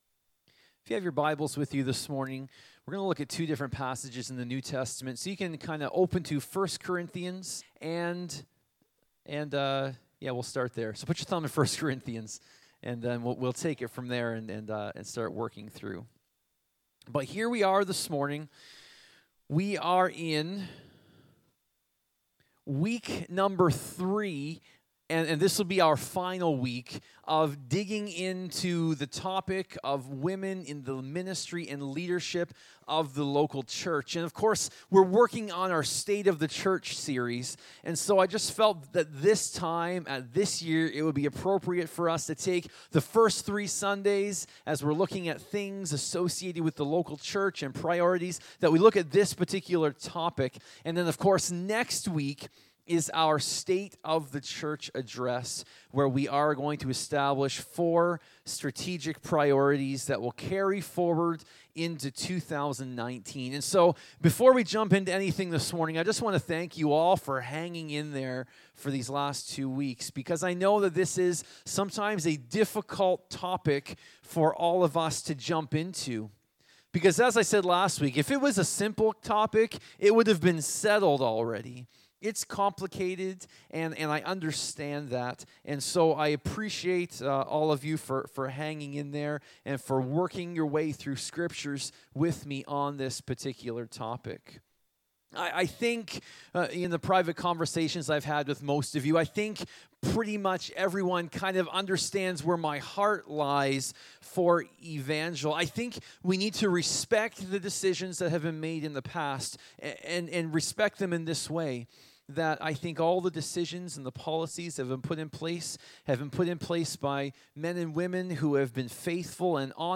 Sermons | Evangel Assembly